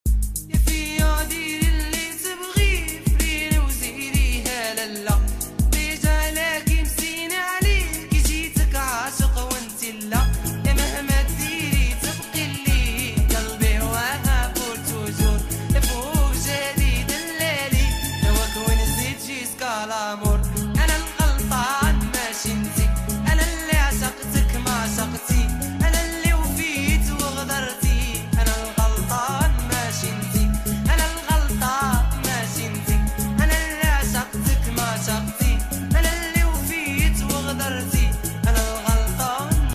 Legendary Car sound effects free download